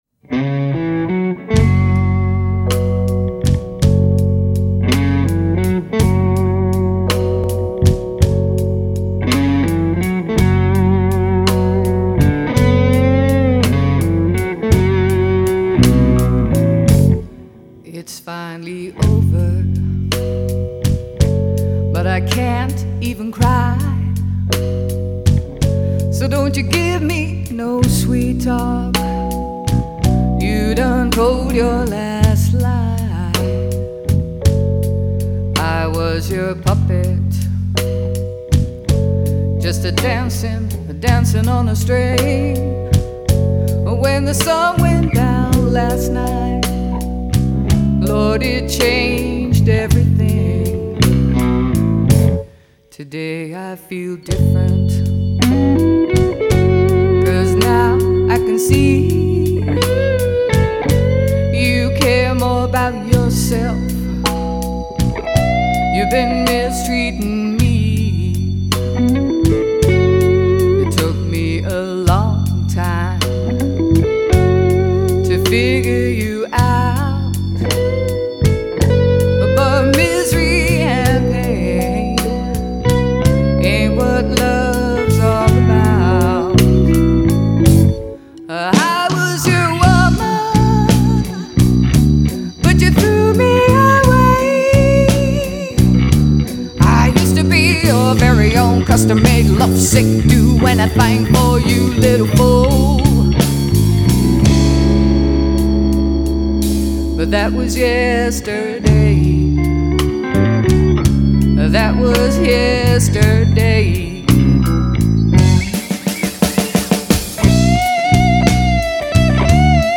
zijn we de studio ingedoken